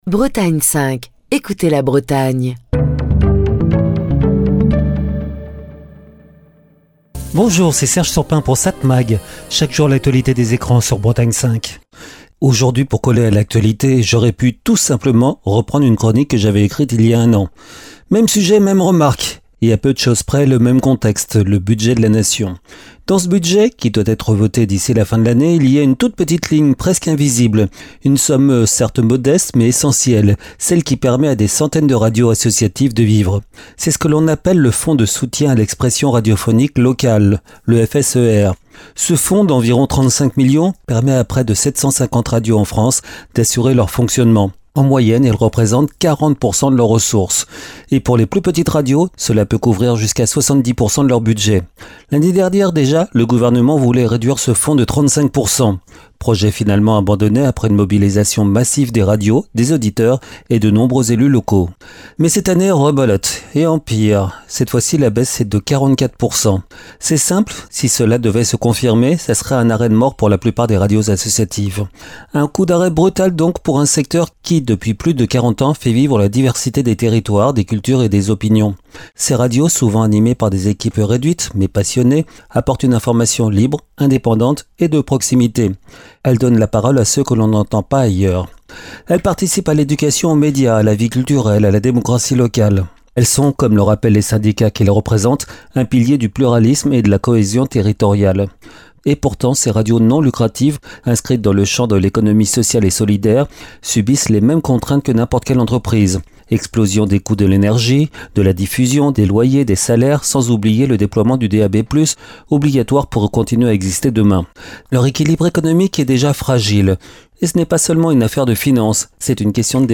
Chronique du 20 octobre 2025.